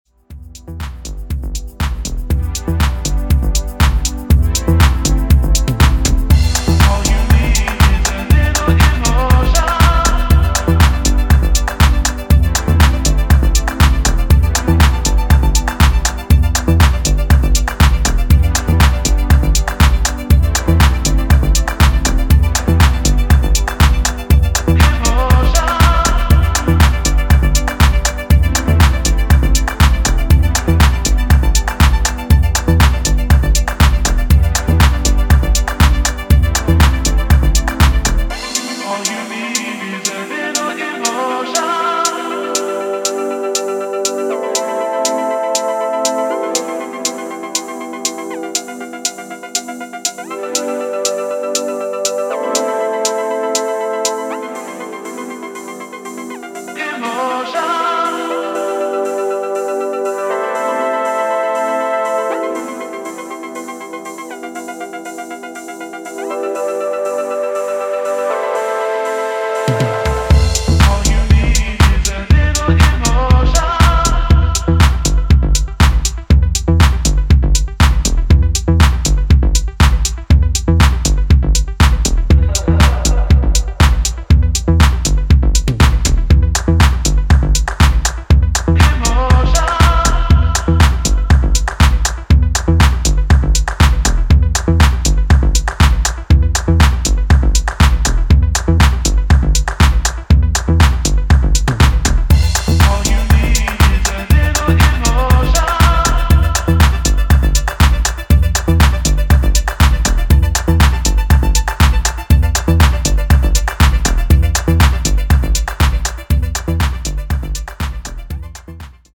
full pelt Dancefloor 4-way